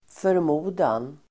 Uttal: [förm'o:dan]